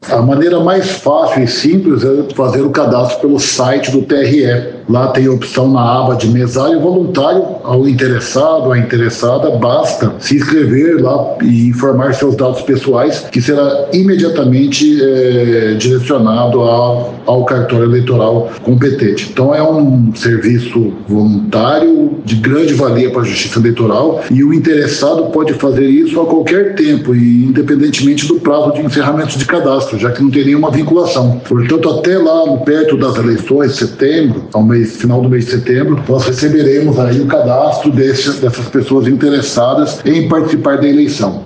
Em entrevista à FM Educativa MS 104.7, o juiz auxiliar da presidência do TRE-MS, Luiz Felipe de Medeiros Vieira, explicou que o cadastro pode ser feito pela internet.